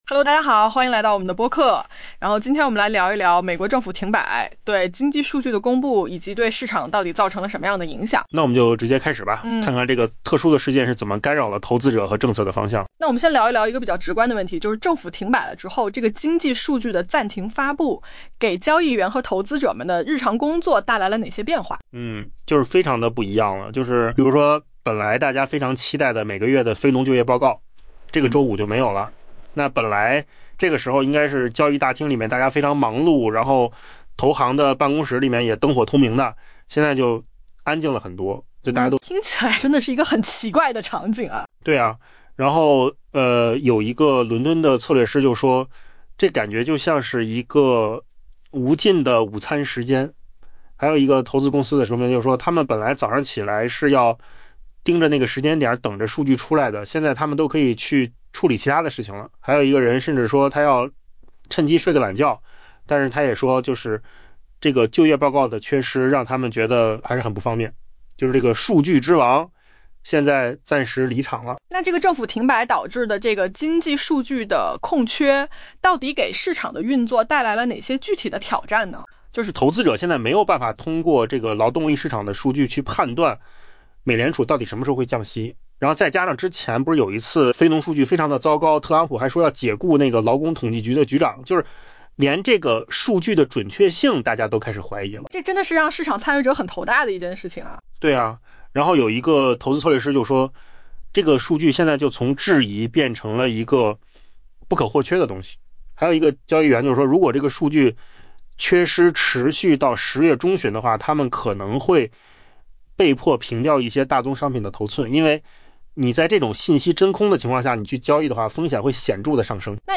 AI 播客：换个方式听新闻 下载 mp3 音频由扣子空间生成 美国月度非农就业报告本应在北京时间周五晚八点半公布，交易员和投资者习惯守在屏幕前，等待这份至关重要的数据。